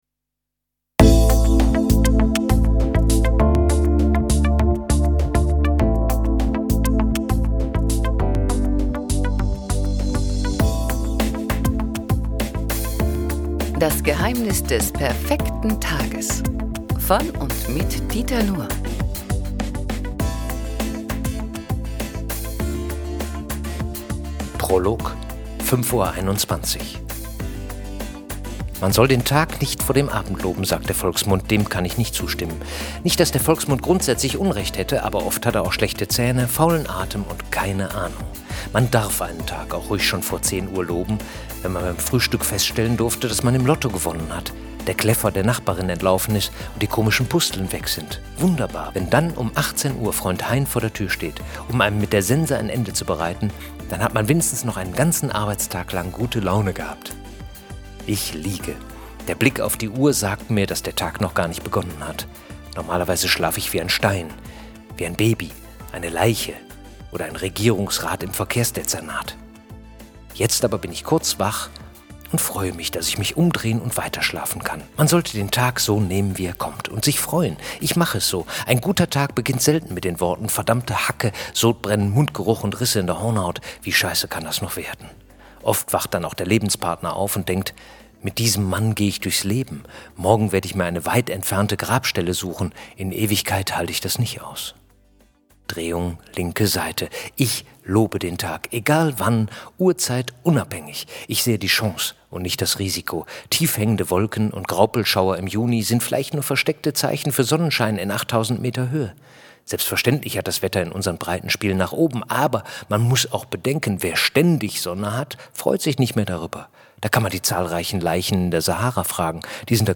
Dieter Nuhr (Sprecher)